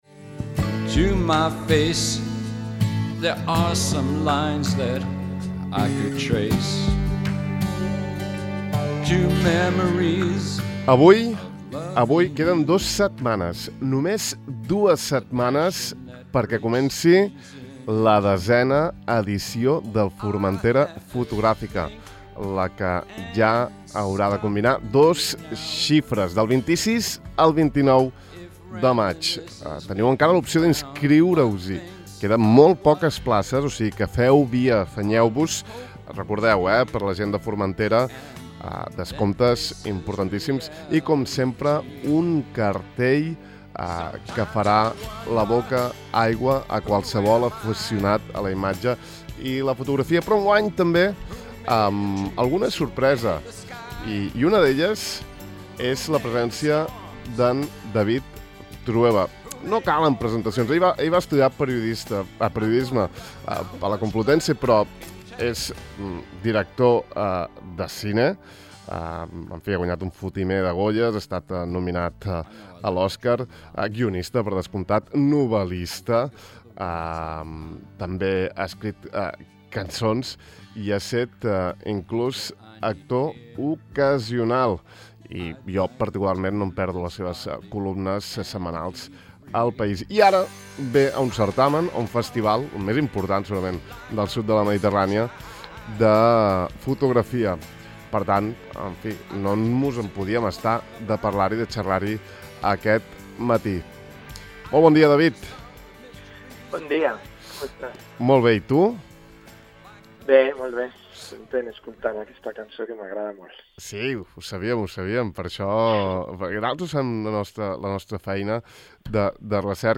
Ahir vàrem conversar amb ell sobre la fotografia en la seva vida. No us perdeu l’entrevista aquí: